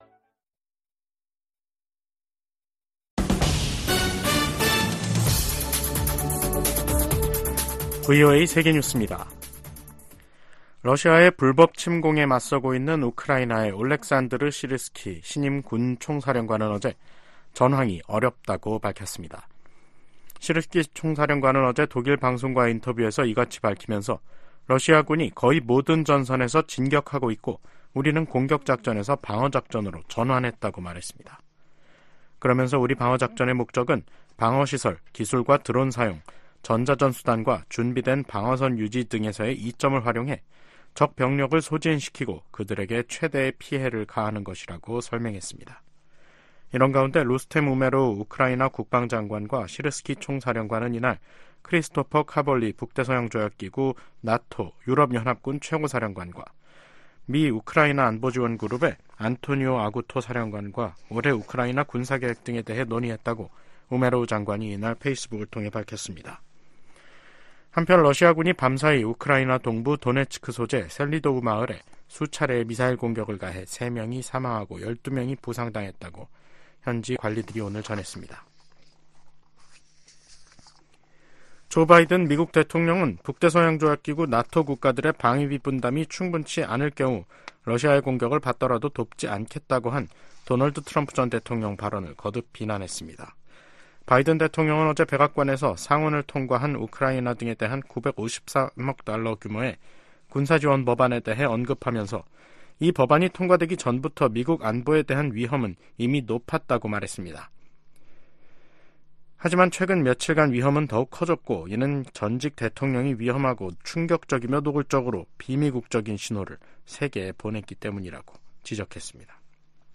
VOA 한국어 간판 뉴스 프로그램 '뉴스 투데이', 2024년 2월 14일 2부 방송입니다. 북한이 또 동해상으로 순항미사일을 여러 발 발사했습니다. 미 국무부는 북한이 정치적 결단만 있으면 언제든 7차 핵실험을 감행할 가능성이 있는 것으로 판단하고 있다고 밝혔습니다. 백악관이 북한의 지속적인 첨단 무기 개발 노력의 심각성을 지적하며 동맹 관계의 중요성을 강조했습니다.